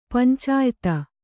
♪ pancāyta